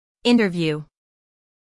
interview-us-female.mp3